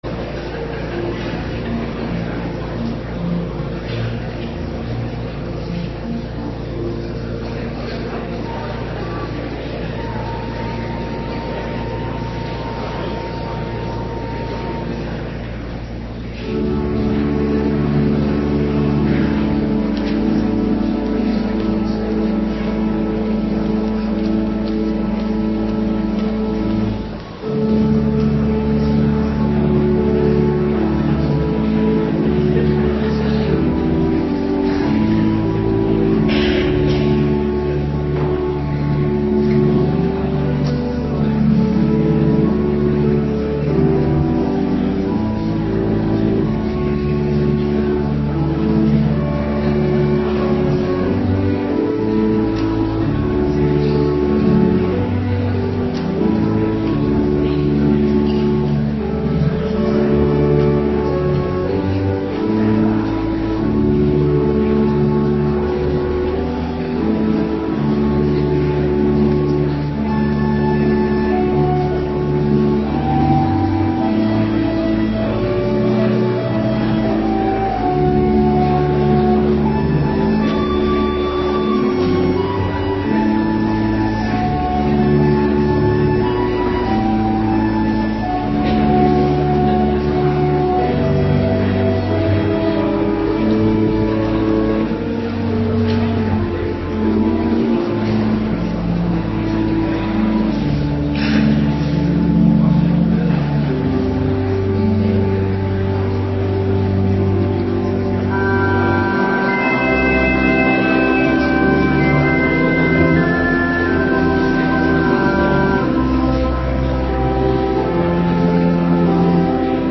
Morgendienst 25 december 2025